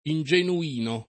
Ingenuino [ in J enu- & no ]